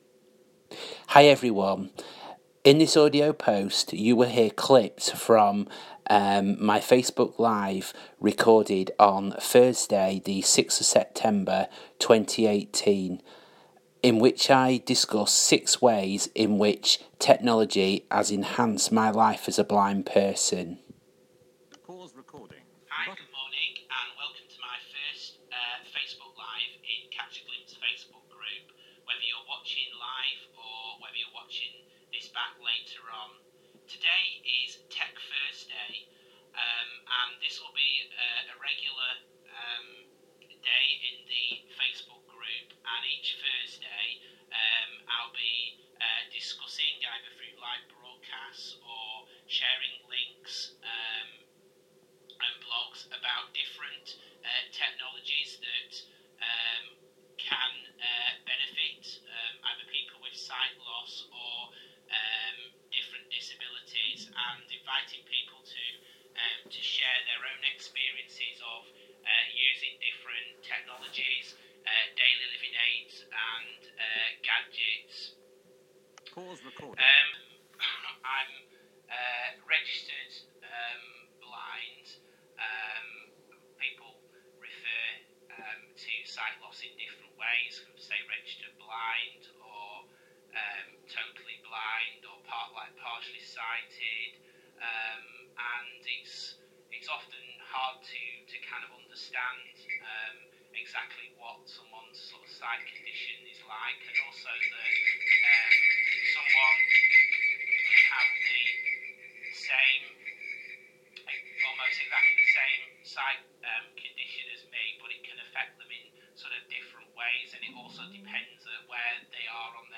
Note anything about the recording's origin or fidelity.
Audio clips from Catch A Glimpse Facebook Group Live Broadcast on 06/09/18 - Six ways technology has enhanced my life as a blind person#glimpseslive